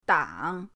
dang3.mp3